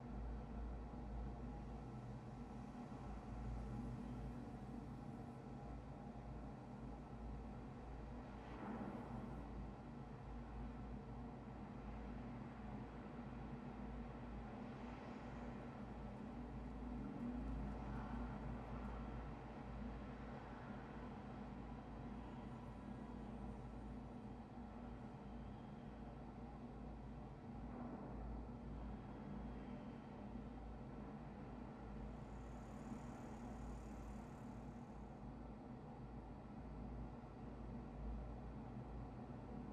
amb_mine.wav